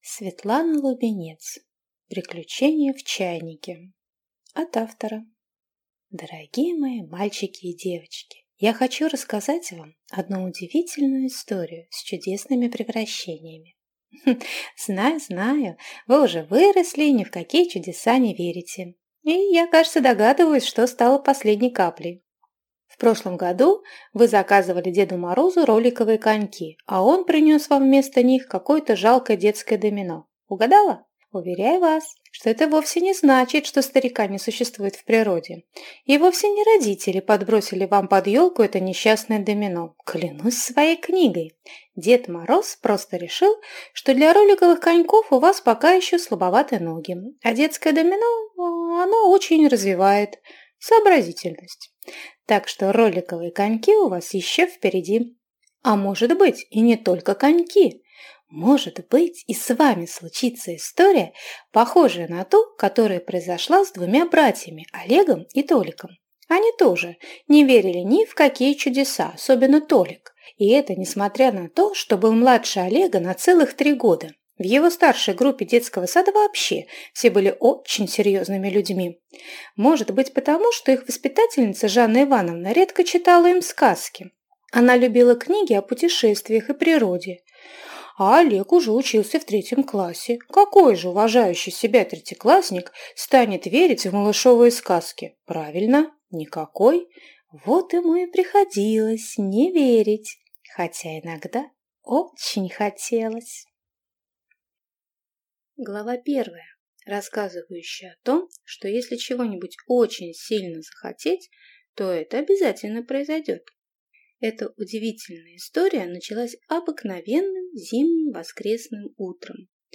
Аудиокнига Приключения в чайнике | Библиотека аудиокниг